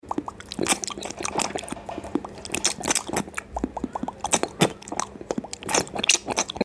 ASMR Lollipop Eating & Mouth Sound Effects Free Download